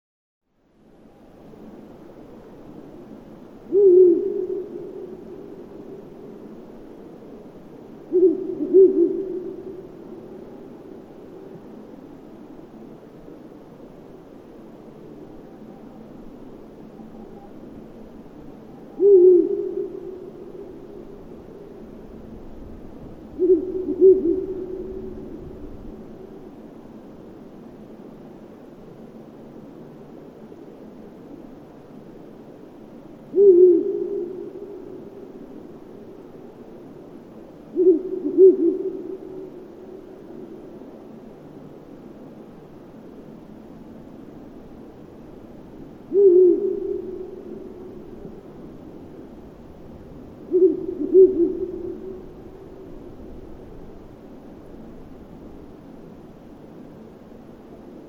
Kuuntele: Viirupöllö
Viirupöllö suosii syrjäseutujen iäkkäitä kuusi- ja koivuvaltaisia havu- ja sekametsiä hakkuuaukeiden, metsäpeltojen ja rantojen tuntumassa. Viirupöllön soidinääni on kumeasti haukkuva, kuuntele ääninäyte!